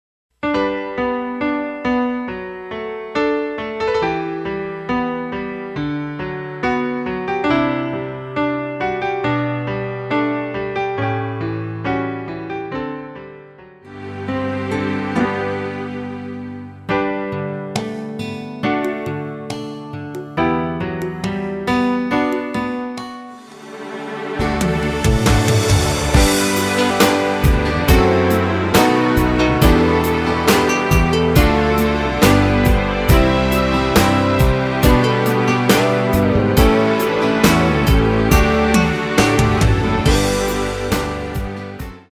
사운드 좋고, 깔끔해요
앞부분30초, 뒷부분30초씩 편집해서 올려 드리고 있습니다.